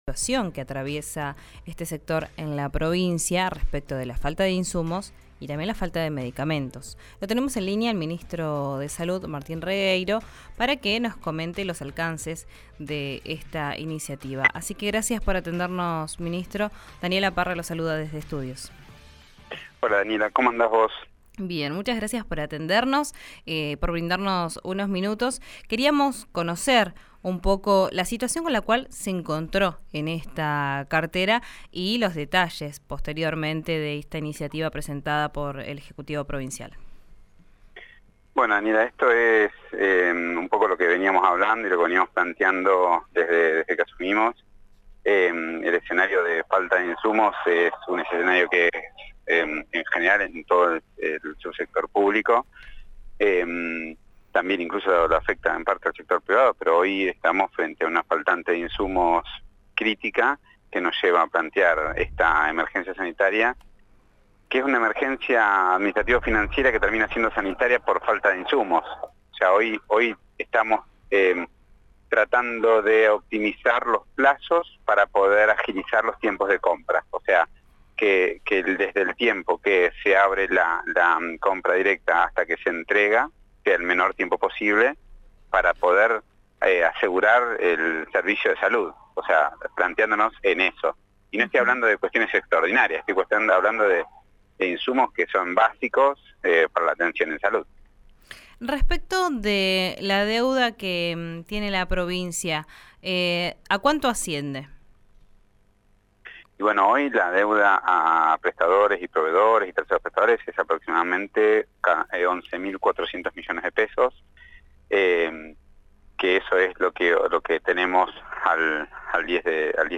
El ministro de Salud, Martín Regueiro, brindó detalles del panorama que hay en Neuquén en diálogo con RÍO NEGRO RADIO y sostuvo que la situación es crítica y se agravó en el último año, por lo que se requiere dar una solución con celeridad.